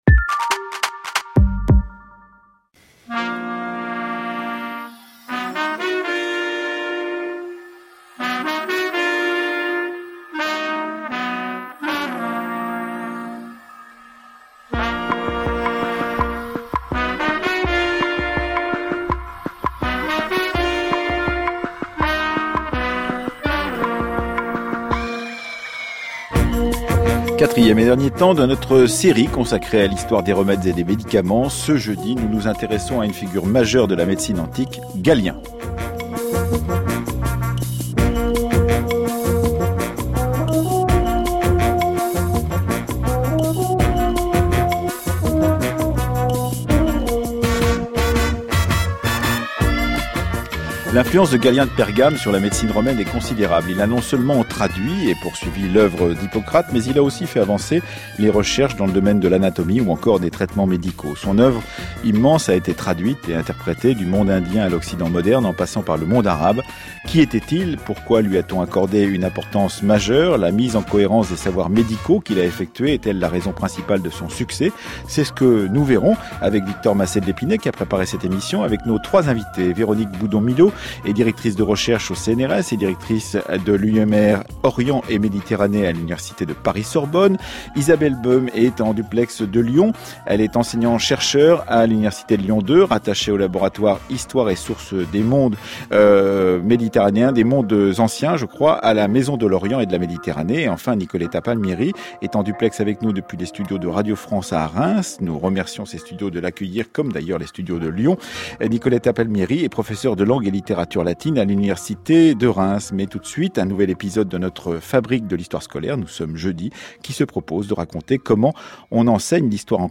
Un débat historiographique consacré à la figure de Galien de Pergame, le médecin romain qui a non seulement traduit et poursuivi l’oeuvre d’Hippocrate, mais qui a aussi fait avancer les recherches dans le domaine de l’anatomie ou des traitements médicaux.